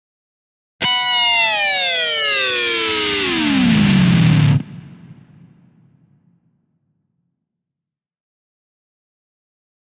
Rock Guitar Distorted FX 3 - Going Down Reef - Double